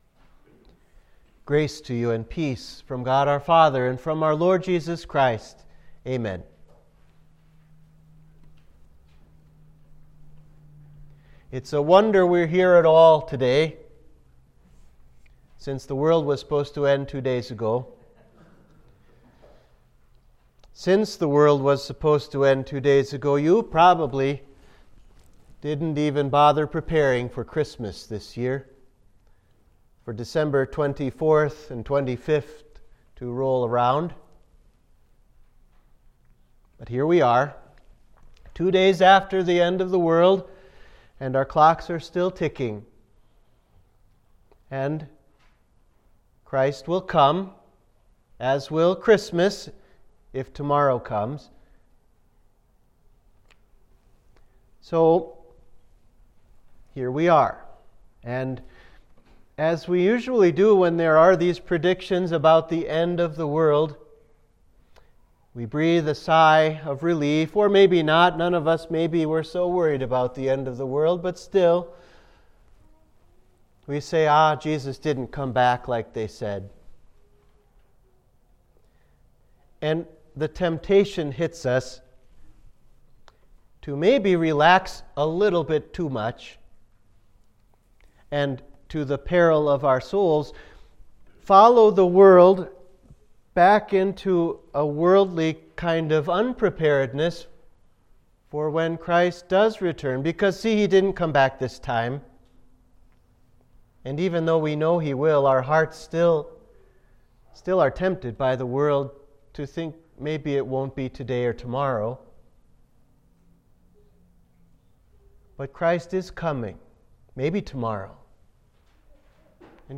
Sermon for Rorate Coeli – Advent 4